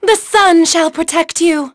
Aselica-Vox_Skill7.wav